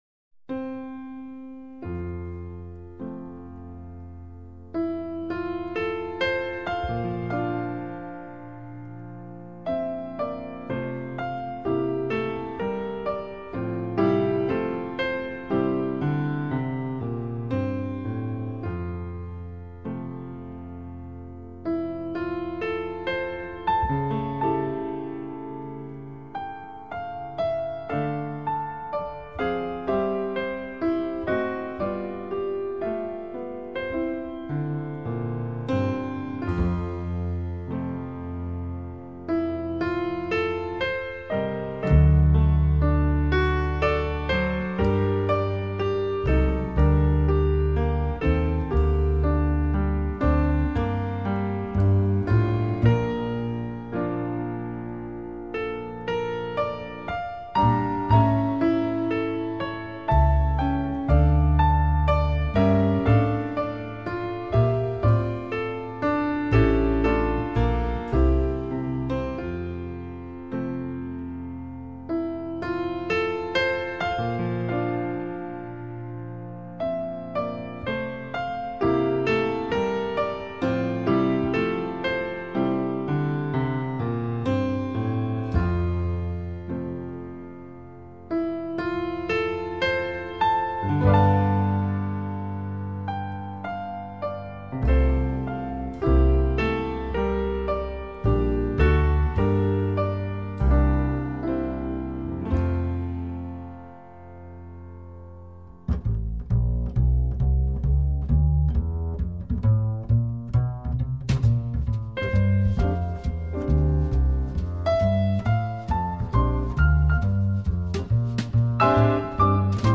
德国版 无压缩音乐 零距离聆听高密度声音层次